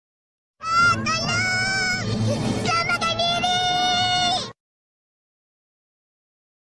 Kategori: Suara viral
Lagi cari sound effect lucu dan viral untuk editan kamu?